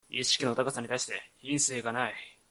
「怒」のタグ一覧
ボイス
男性